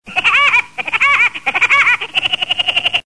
Здесь можно скачать или слушать онлайн любимые моменты из мультфильмов в хорошем качестве.
Стандартный звук смеха дятла Вуди Вудпекера чистый без музыки